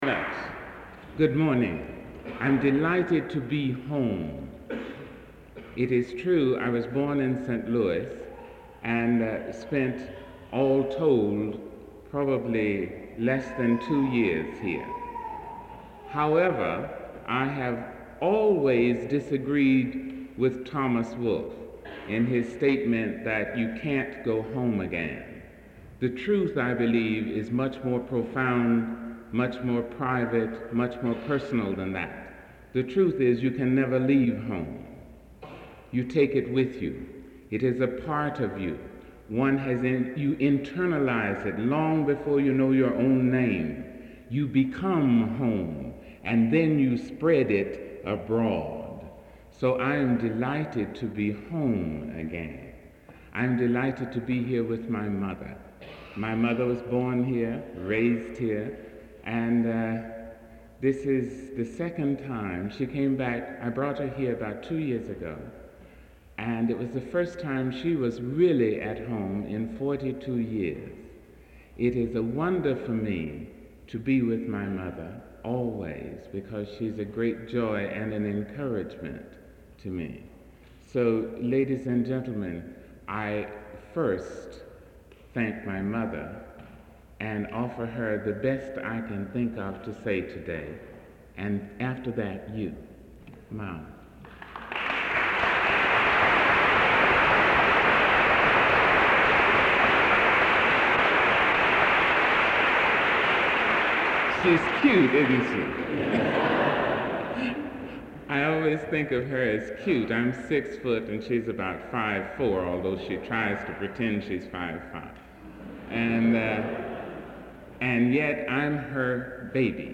Lecture Title
Martin Luther King Symposium